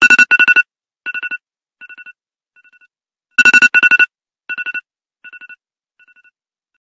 ios_timer_sound.mp3